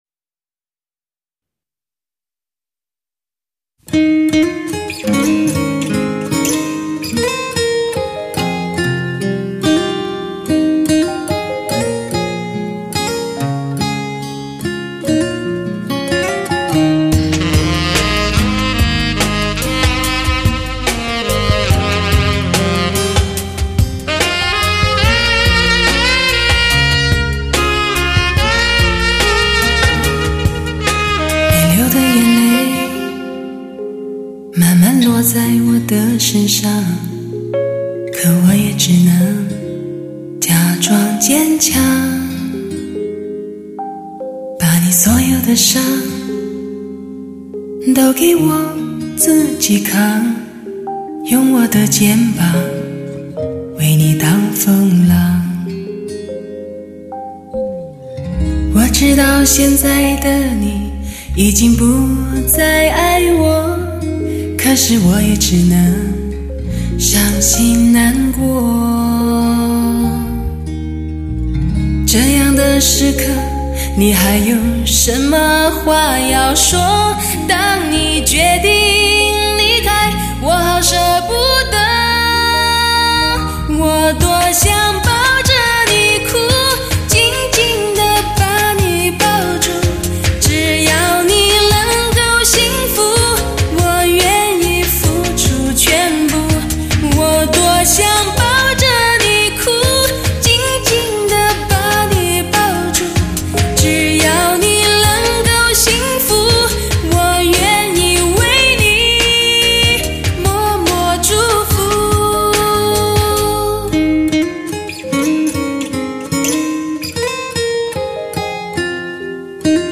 震天动地，强效动感，优美动人的完美旋律。
女声低音炮，发挥音乐的极致，最值得收藏的音乐极品。
源自心灵深处的倾诉，诠释属于梦的境界，音色淳厚优美，典雅华丽而委婉。